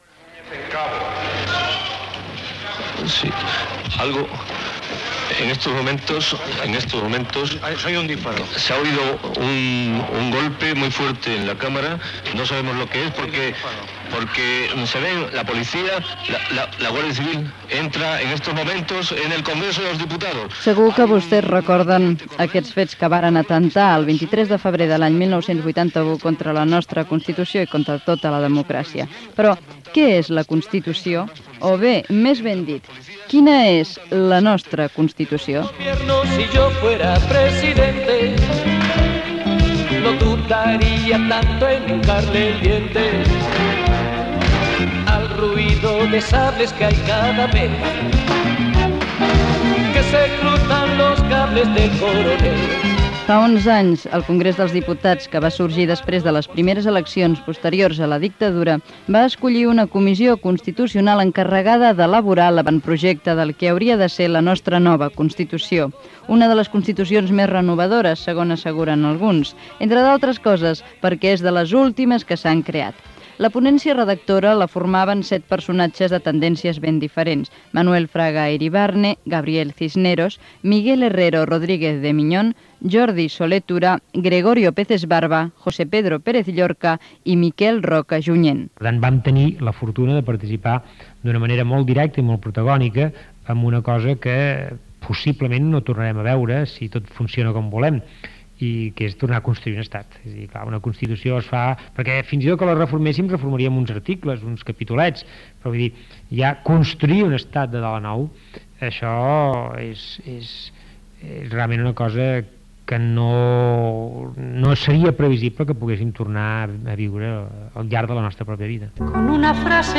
Reportatge sobre la Constitució espanyola de 1978, amb declaracions dels polítics Miquel Roca i Lluís Maria Xirinacs i del periodista Fernando Ónega
Informatiu